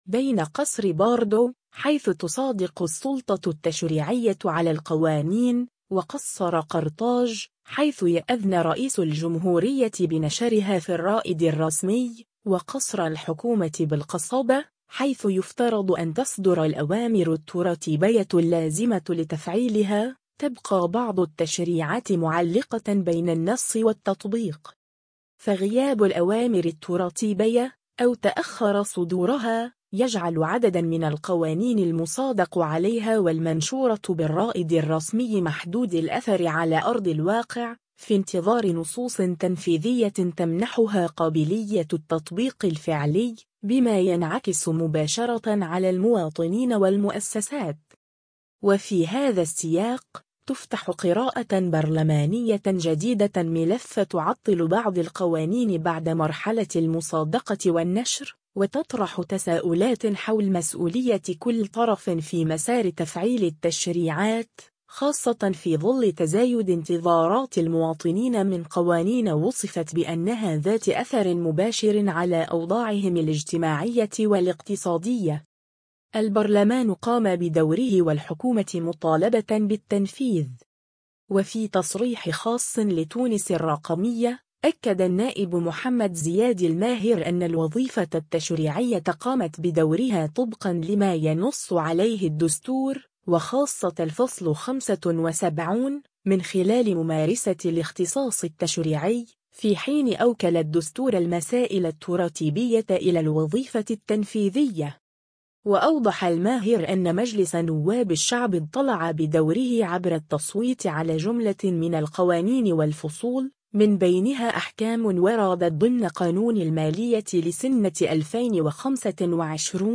وفي تصريح خاص لـ”تونس الرقمية”، أكد النائب محمد زياد الماهر أن الوظيفة التشريعية قامت بدورها طبقا لما ينص عليه الدستور، وخاصة الفصل 75، من خلال ممارسة الاختصاص التشريعي، في حين أوكل الدستور المسائل الترتيبية إلى الوظيفة التنفيذية.